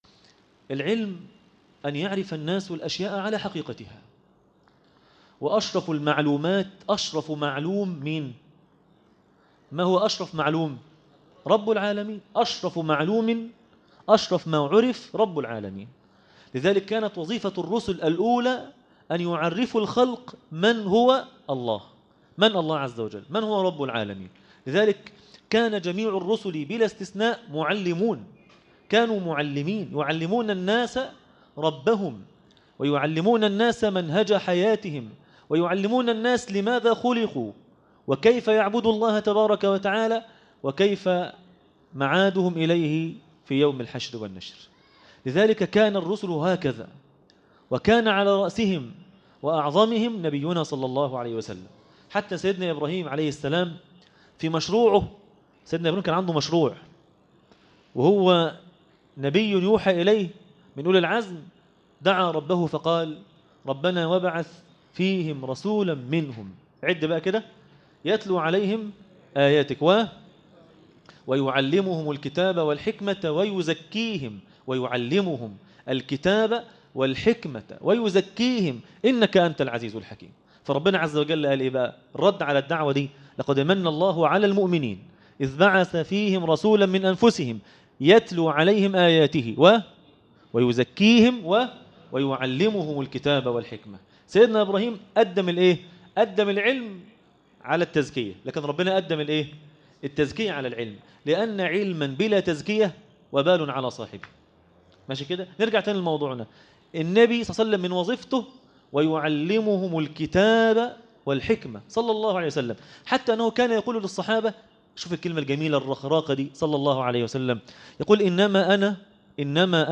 عنوان المادة النبي (صلي الله عليه وسلم ) معلما - درس التراويح ليلة 9 رمضان 1437هـ